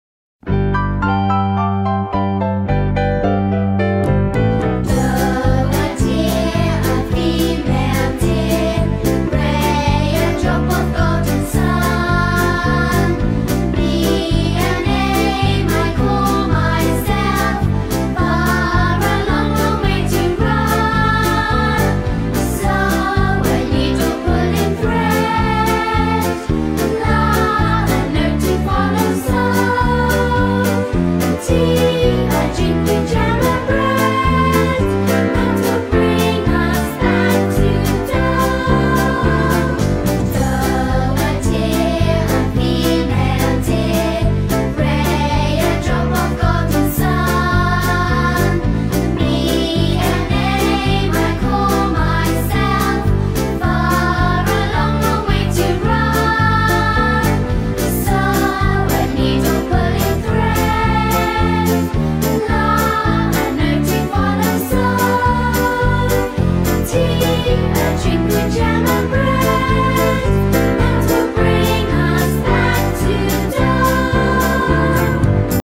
Do-re-mi-Instrumental.m4a